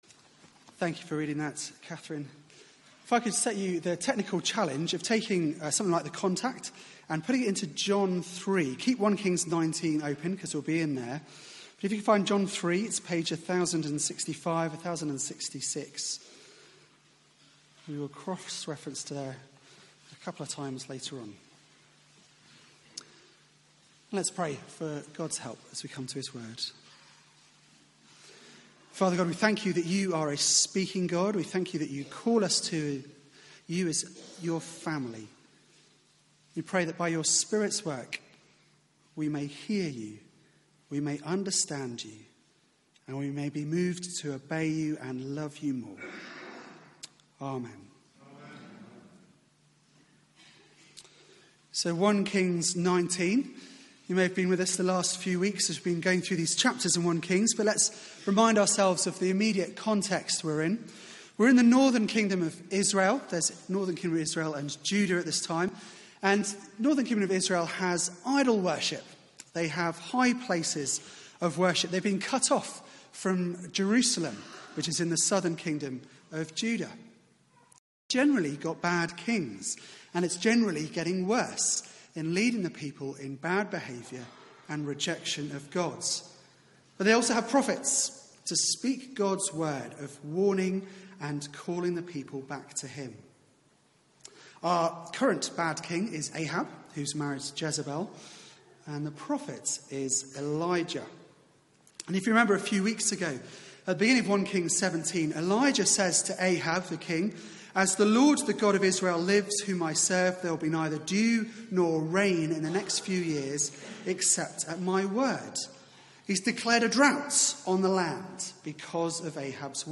Sermon (11:00 Service)Play